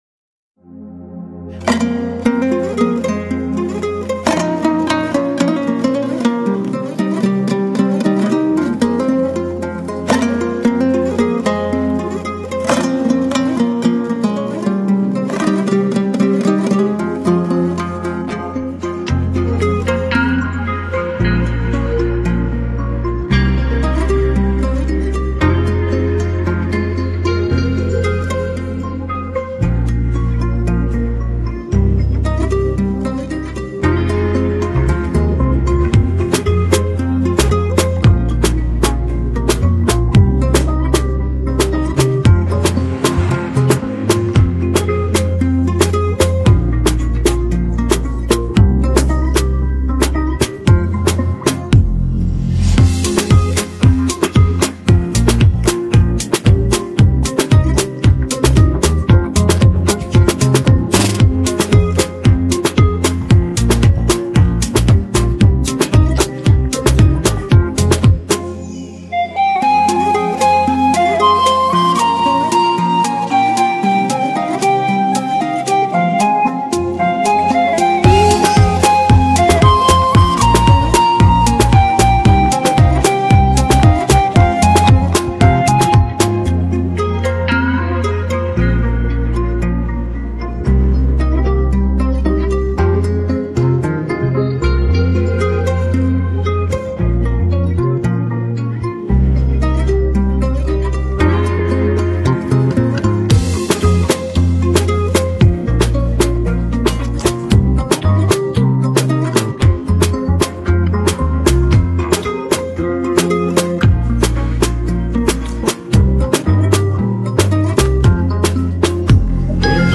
Қазақша әндер